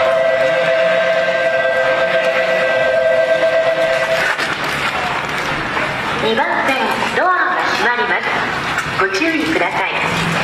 ベル こちらも私鉄で多用。